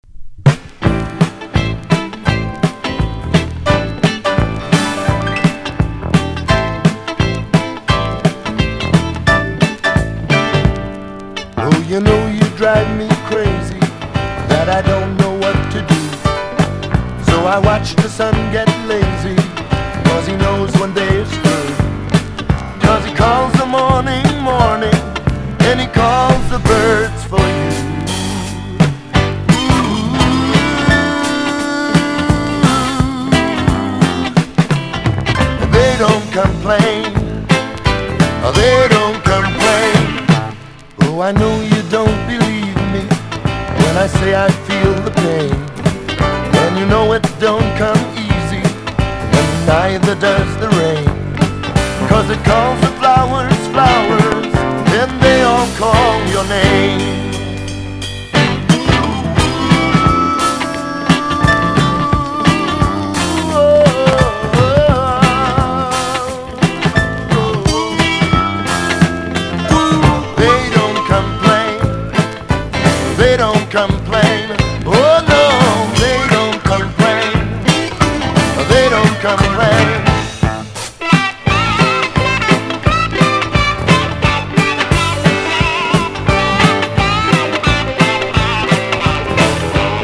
シンセが効果的に使われたスペース・ファンクな1枚！